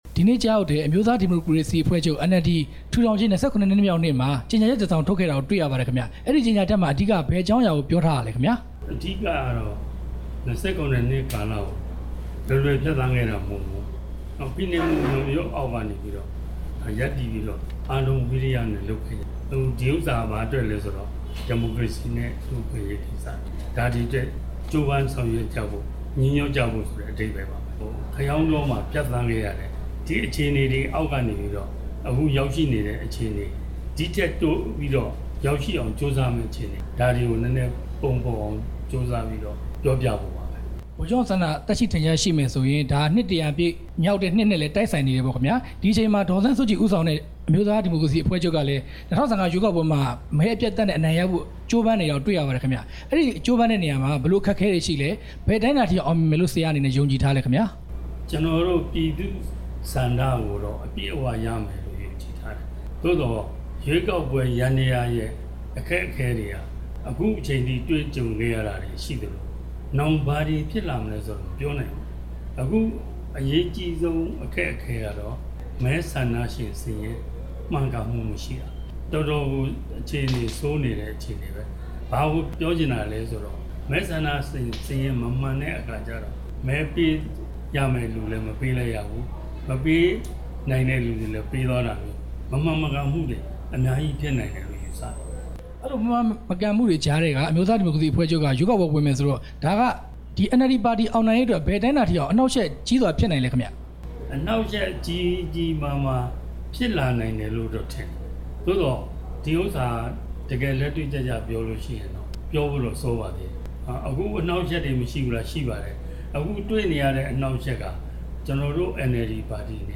NLD ၂၇ နှစ်မြောက်နေ့ ကြေညာချက်အကြောင်း ဦးဉာဏ်ဝင်းနဲ့ မေးမြန်းချက်